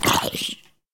Minecraft Version Minecraft Version snapshot Latest Release | Latest Snapshot snapshot / assets / minecraft / sounds / mob / zombie / hurt1.ogg Compare With Compare With Latest Release | Latest Snapshot
hurt1.ogg